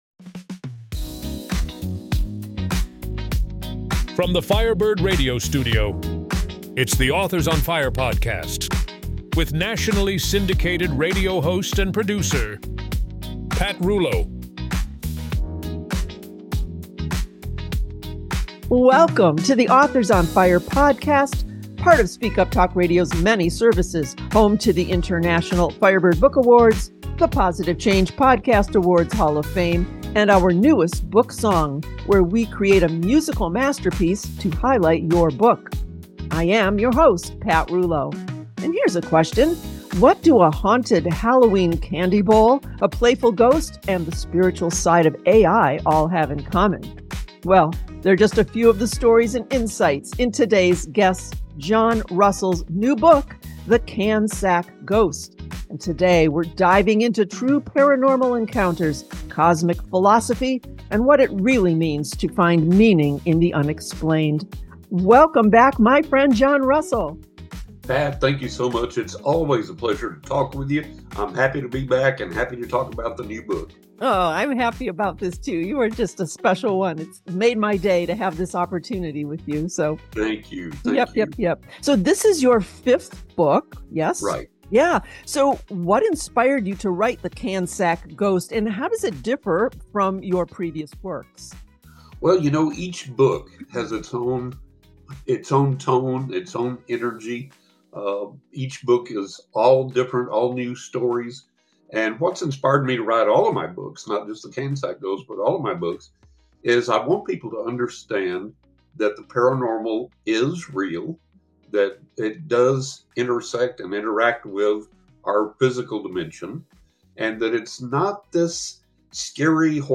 Interview – THE CAN SACK GHOST